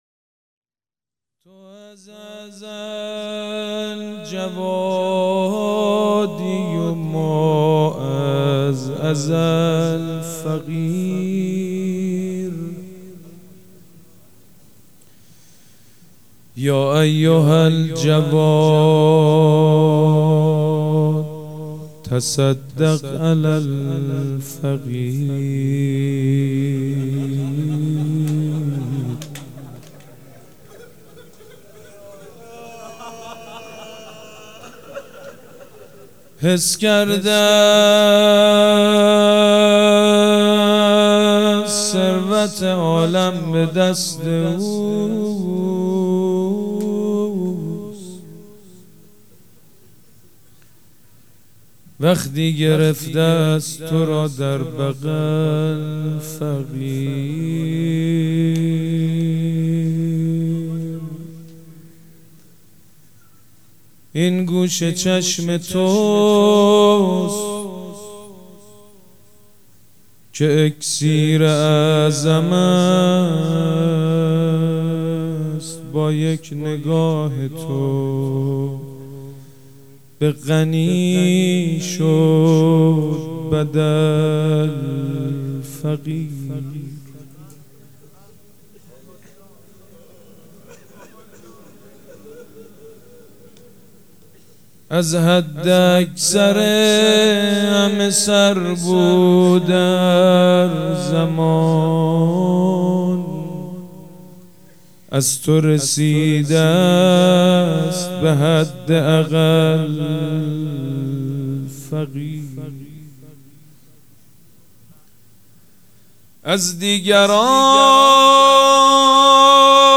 هیئت ریحانه الحسین سلام الله علیها
روضه
مداح
شهادت امام جواد (ع)